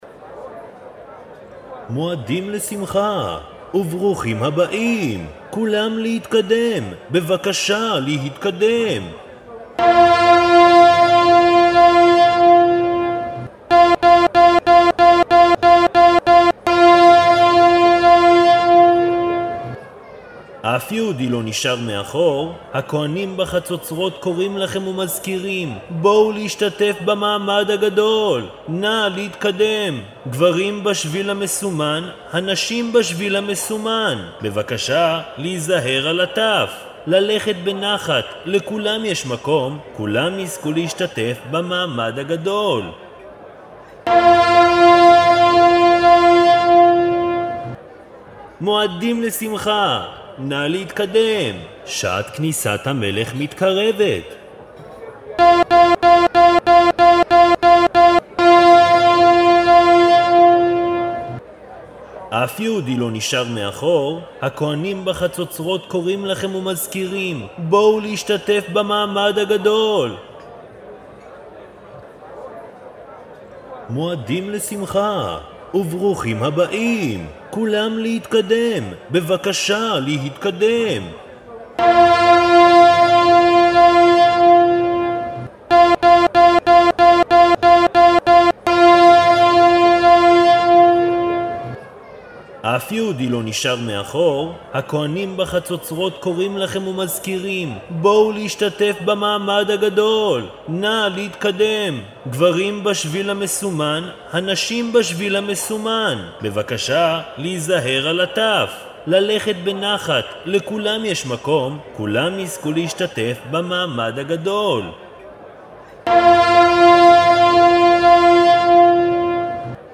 פס קול לפתיחה